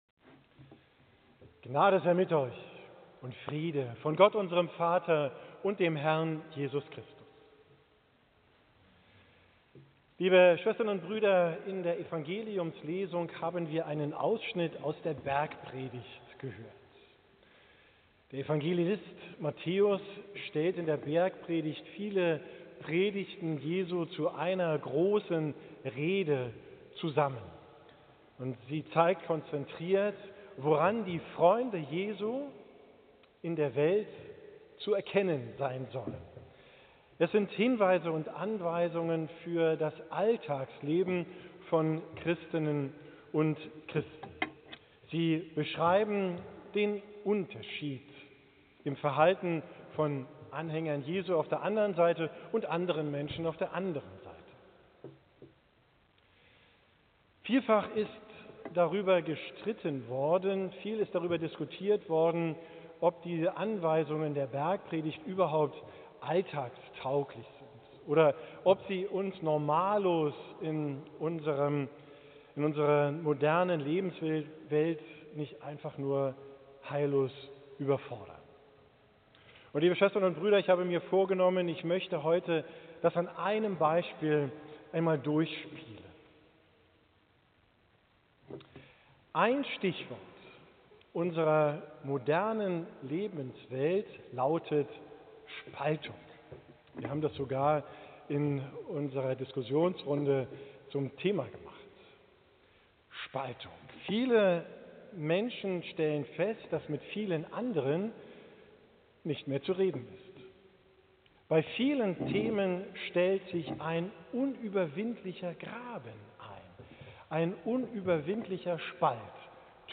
Predigt vom 21.